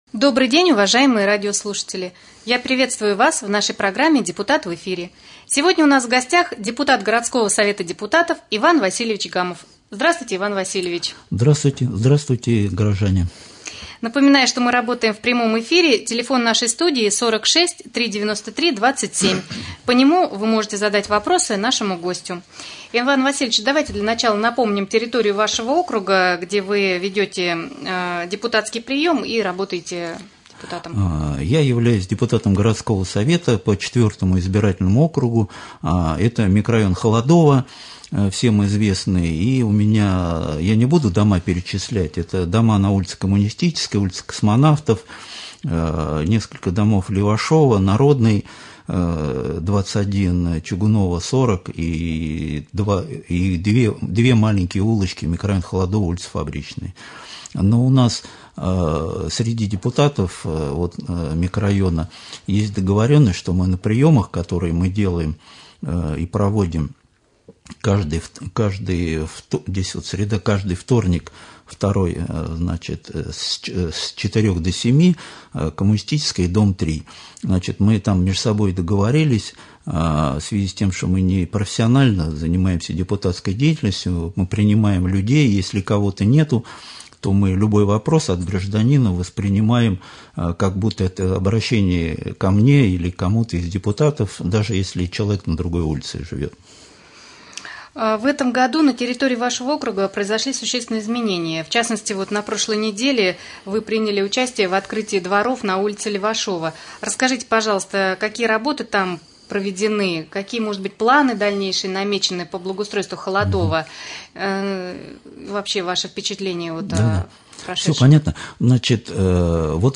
Прямой эфир с депутатом городского поселения Раменское заместителем генерального директора предприятия «Торговые ряды Раменское» Иваном Васильевичем Гамовым.